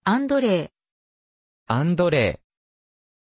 Pronunciación de Andrée
pronunciation-andrée-ja.mp3